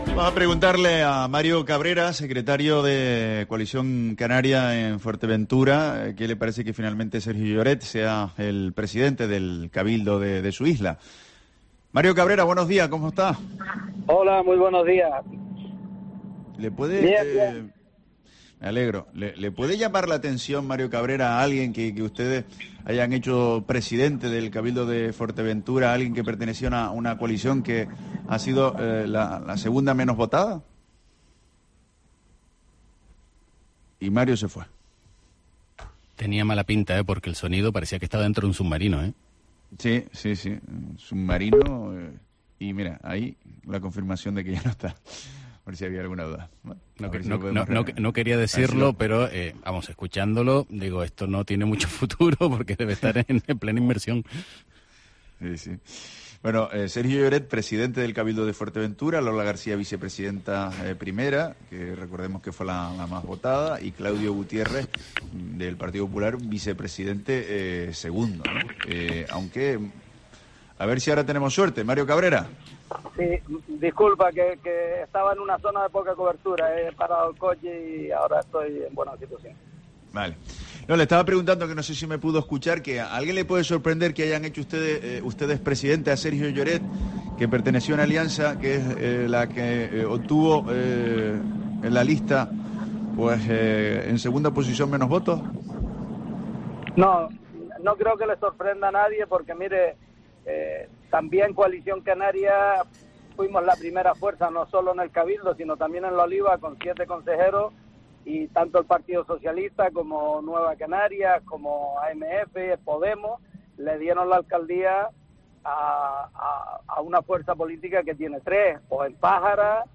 Entrevista Mario Cabrera, secretario general de CC en Fuerteventura